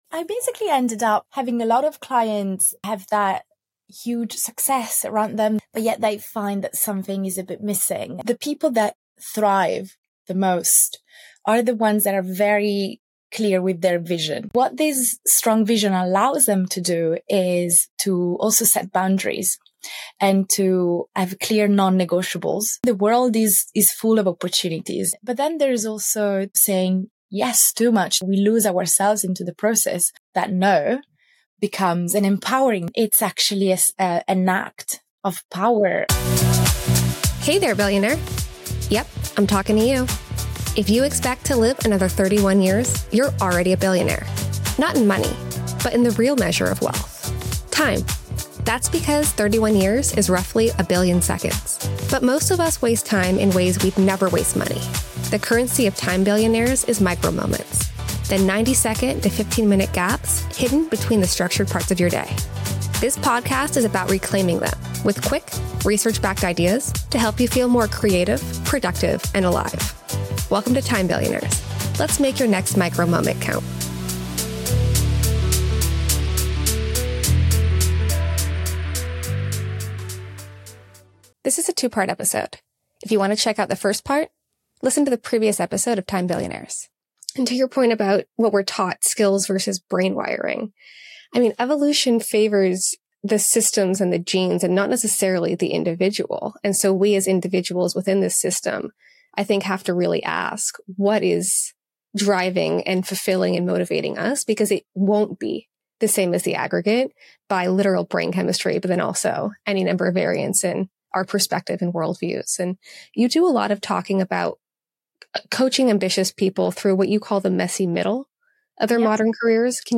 Are you successful on paper but feel like something's missing? In Part 2 of this conversation on the Time Billionaires podcast